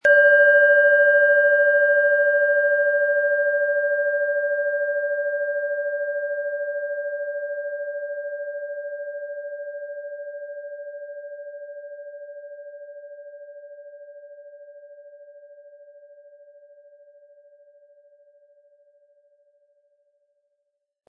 Planetenton 1
Unter dem Artikel-Bild finden Sie den Original-Klang dieser Schale im Audio-Player - Jetzt reinhören.
Durch die traditionsreiche Herstellung hat die Schale stattdessen diesen einmaligen Ton und das besondere, bewegende Schwingen der traditionellen Handarbeit.
Der gratis Klöppel lässt die Schale wohltuend erklingen.
MaterialBronze